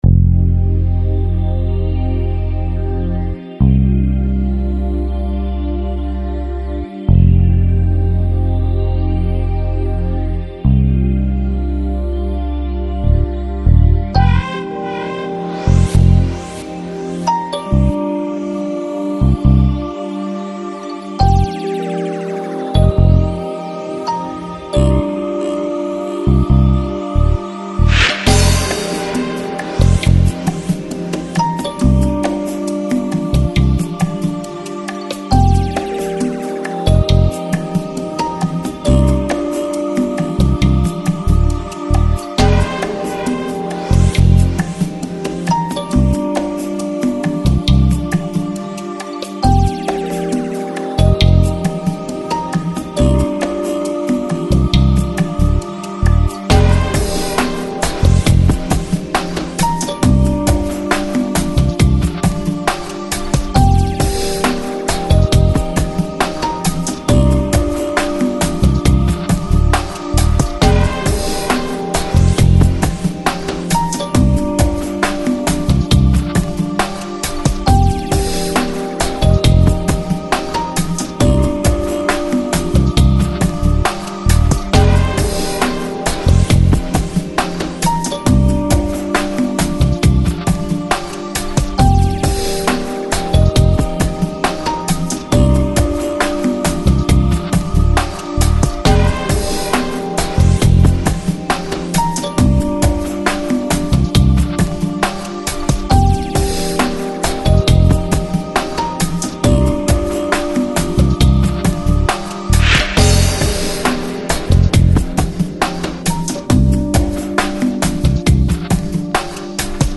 Жанр: Electronic, Downtempo, Lounge, Chill Out